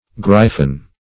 gryfon - definition of gryfon - synonyms, pronunciation, spelling from Free Dictionary
gryfon - definition of gryfon - synonyms, pronunciation, spelling from Free Dictionary Search Result for " gryfon" : The Collaborative International Dictionary of English v.0.48: Gryfon \Gryf"on\, n. [Obs.]